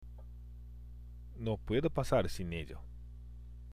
（ノ　プエド　パサール　シン　エジョ）